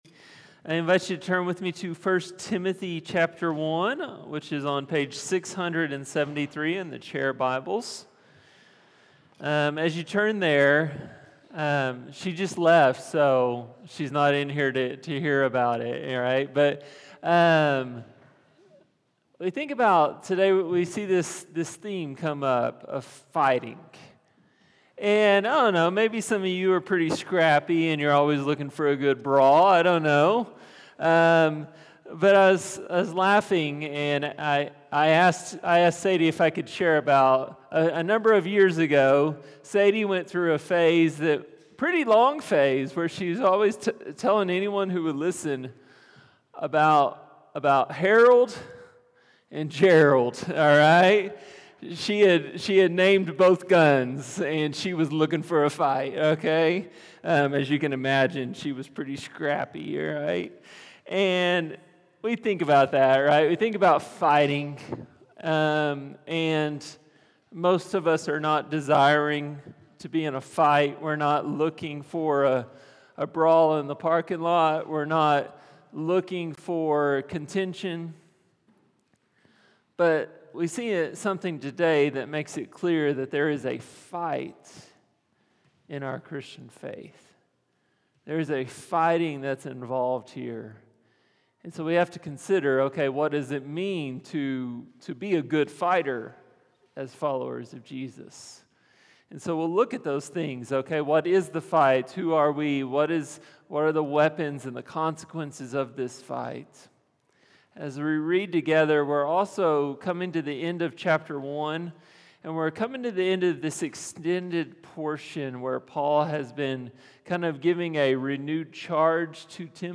Sermons | HopeValley Church // West Jordan, UT